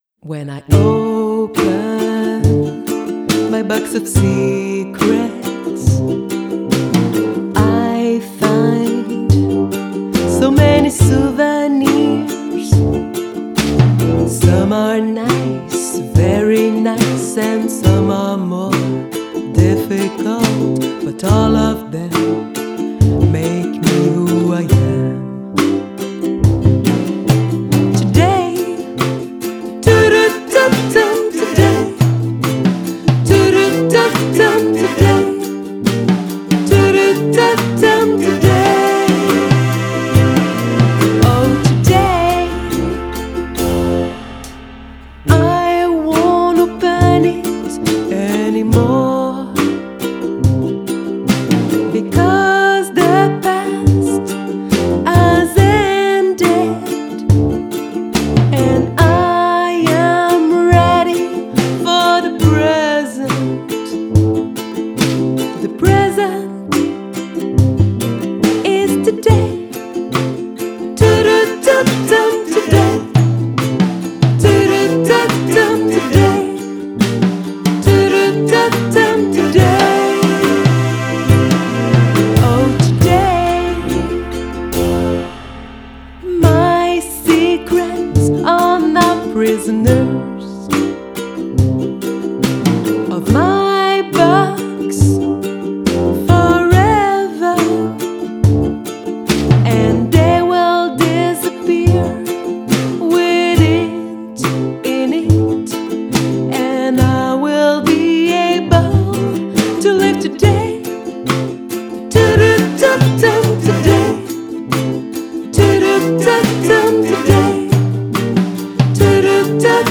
Genre: Folk, Pop